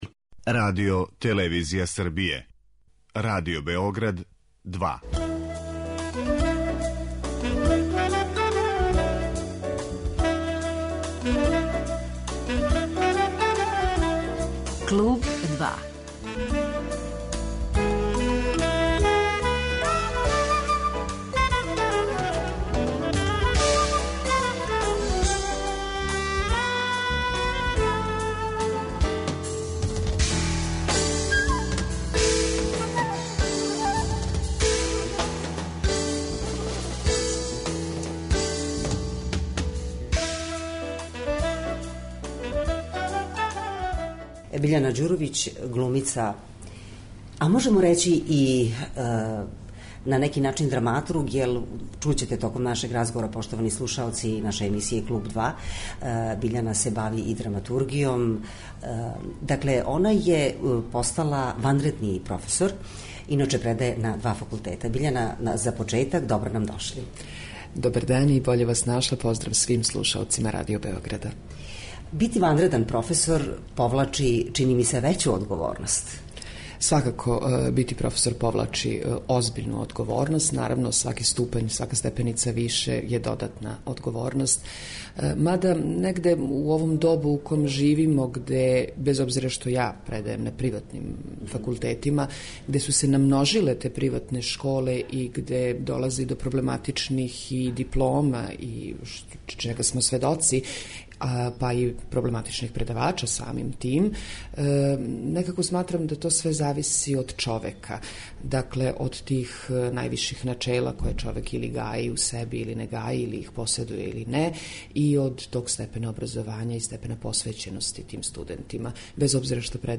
Гост емисије Клуб 2 је Биљана Ђуровић, драмска уметница.